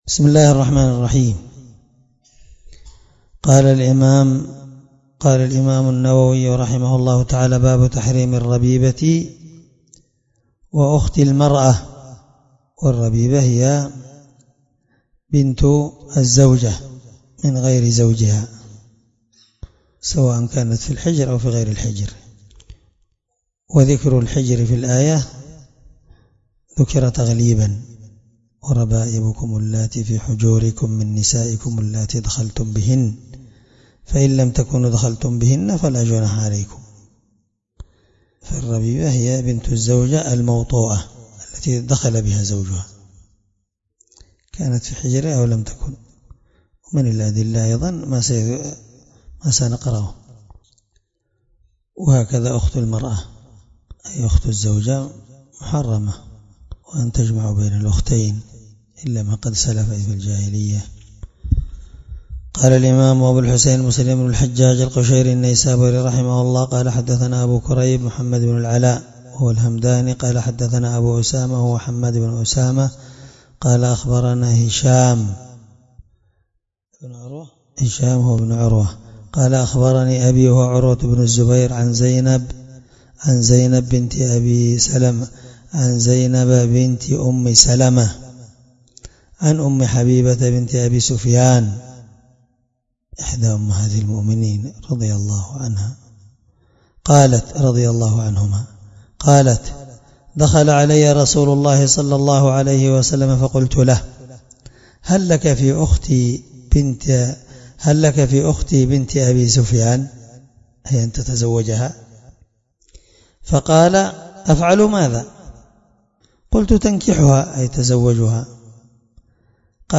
الدرس4من شرح كتاب الرضاع حديث رقم(1449) من صحيح مسلم